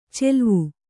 ♪ celvu